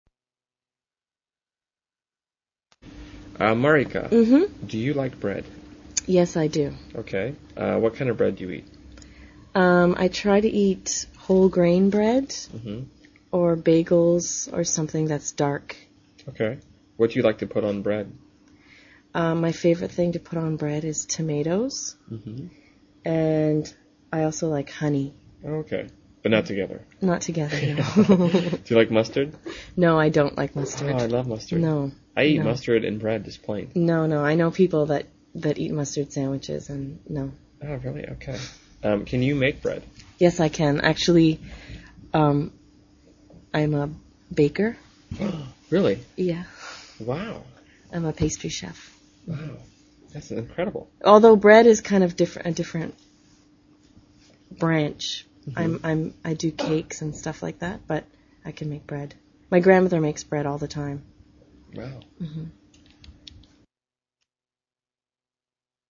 英语高级口语对话正常语速16:面包（MP3）